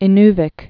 (ĭ-nvĭk)